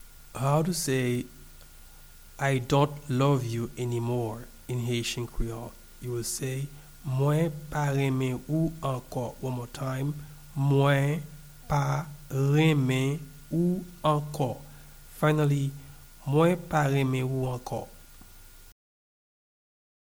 Pronunciation and Transcript:
I-dont-love-you-anymore-in-Haitian-Creole-Mwen-pa-renmen-ou-anko-pronunciation.mp3